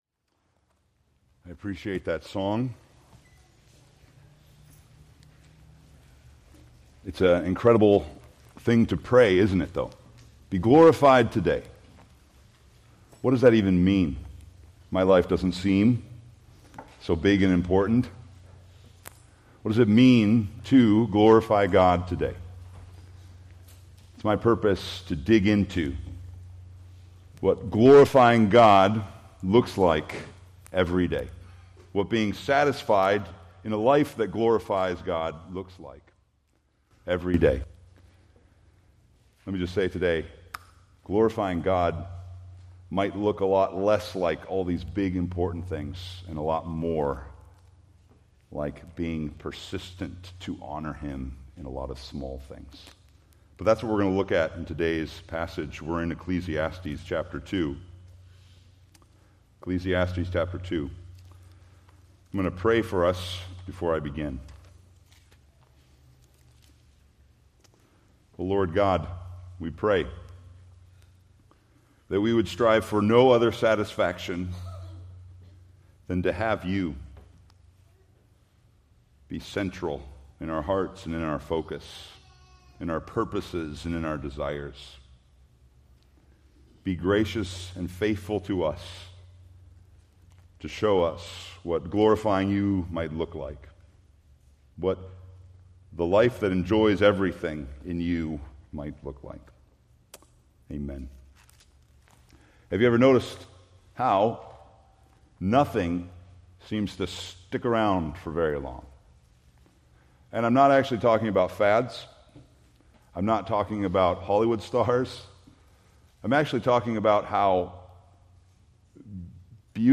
Preached July 13, 2025 from Ecclesiastes 2